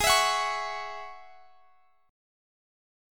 G#7sus4#5 Chord
Listen to G#7sus4#5 strummed